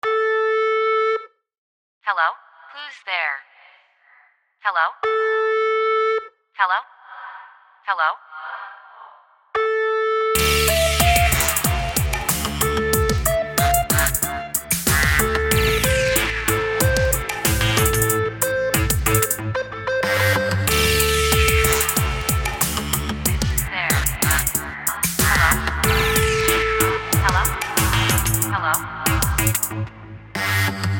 Free modern/dubstep style ringtone.